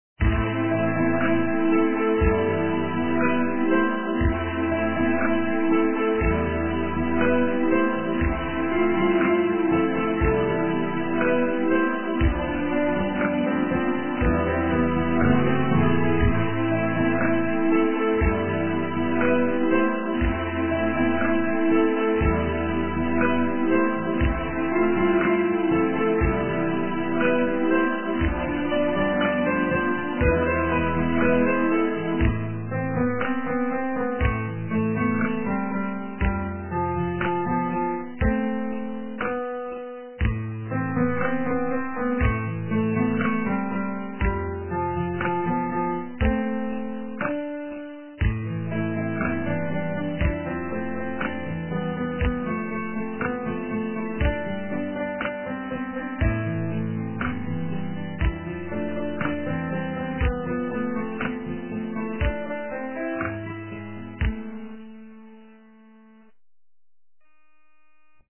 русская эстрада